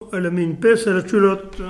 Localisation Nieul-sur-l'Autise
Catégorie Locution